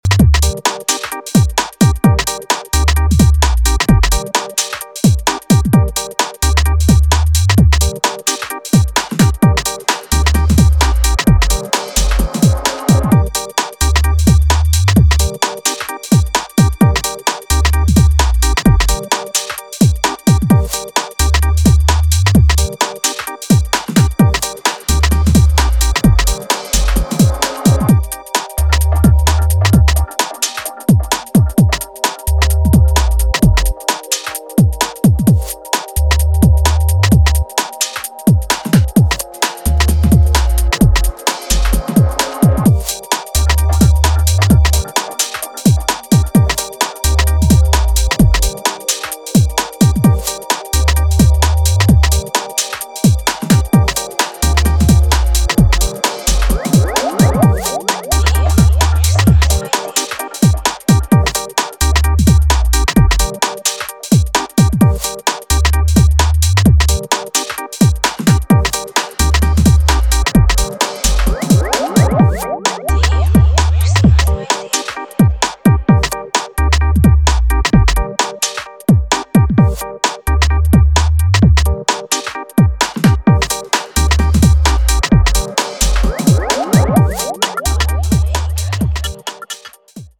Breakbeat , House